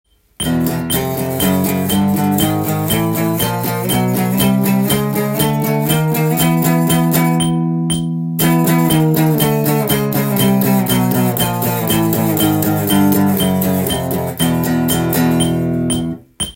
【パワーコードストローク練習】オリジナルTAB譜
８分音符
パワーコードを０フレットから半音階で１２フレットまで上がっていく
TAB譜　メトロノームのテンポは１２０です。
ダウンだけでも良いですし、ダウンアップでもOKです。